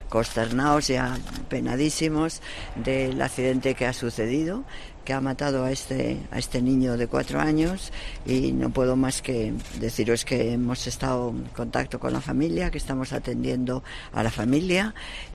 Además, en declaraciones a los periodistas junto a la delegada de Medioambiente y Movilidad, Inés Sabanés, Carmena ha declarado que se trata de "un momento muy triste" y que en el Ayuntamiento estaban "consternados y apenadísimos" por este accidente.